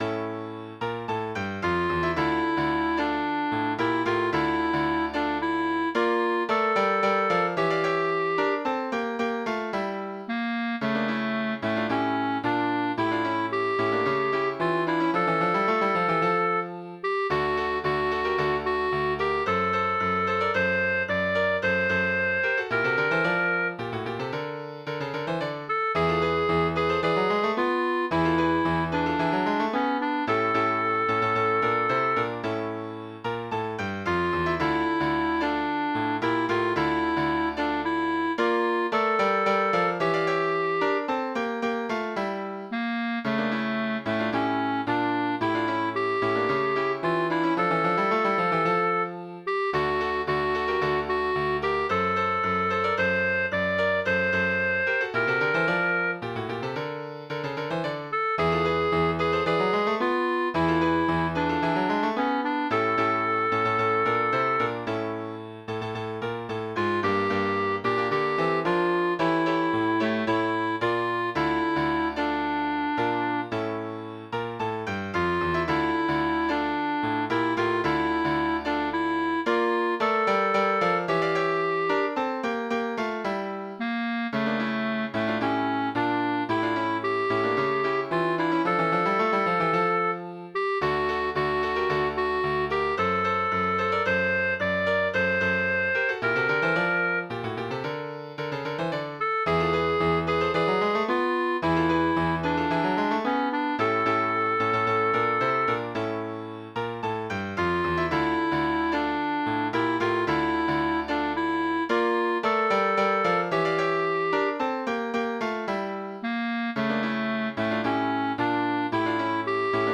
ТИП: Пісня
СТИЛЬОВІ ЖАНРИ: Ліричний
ВИД ТВОРУ: Авторська пісня